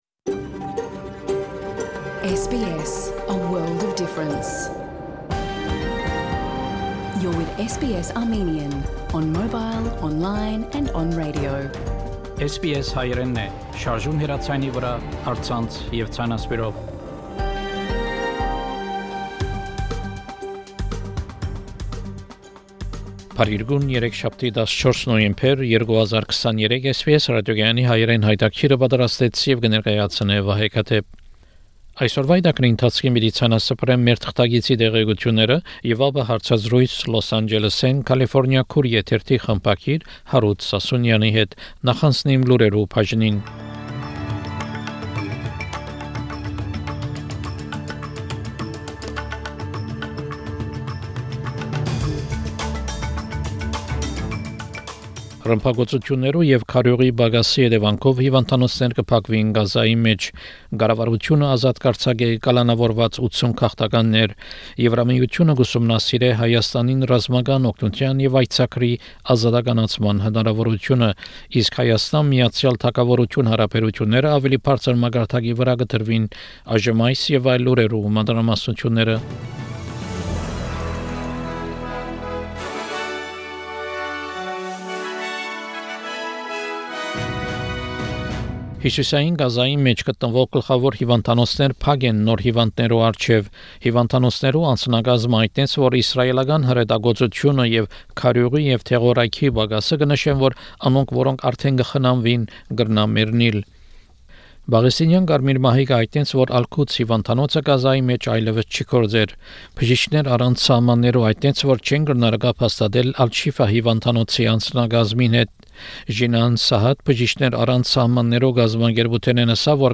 SBS Armenian news bulletin – 14 November 2023
SBS Armenian news bulletin from 14 November program.